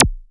Modular Perc 05.wav